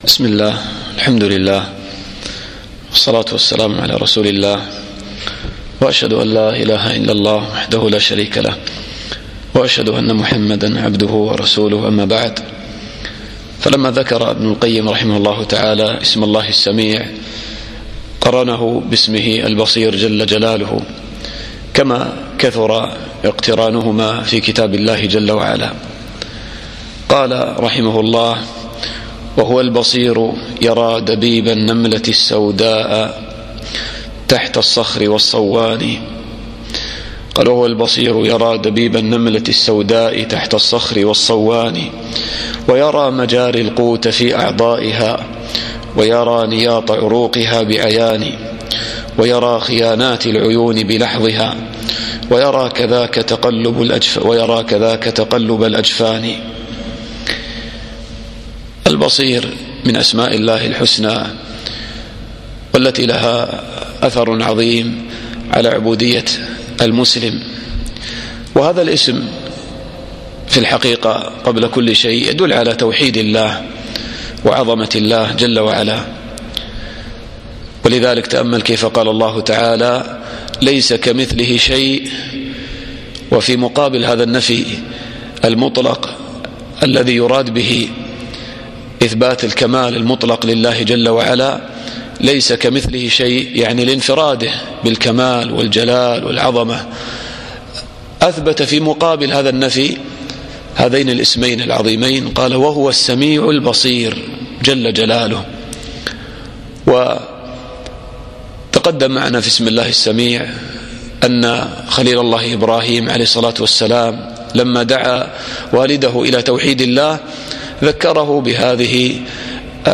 الدرس التاسع